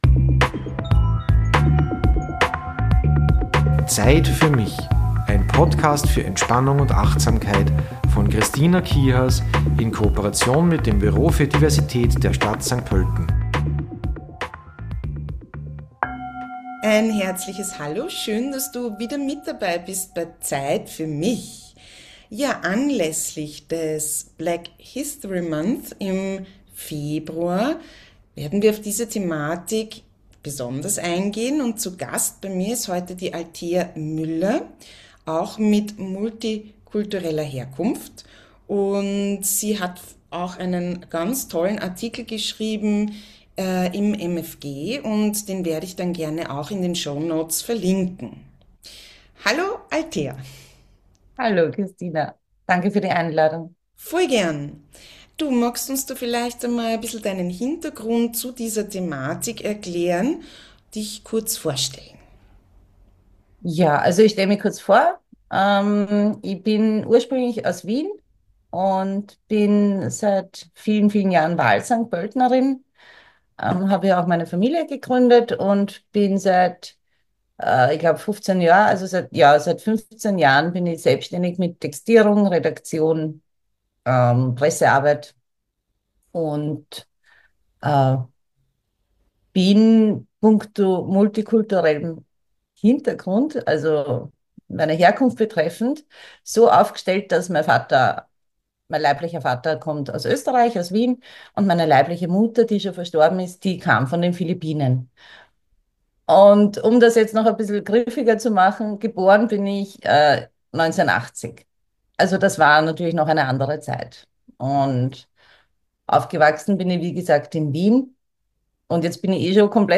Wir freuen uns im Übrigen sehr, dass unser Podcast nun auch über das Campus & City Radio St. Pölten ausgestrahlt wird - Sende-Termin: Sa, 17 Uhr.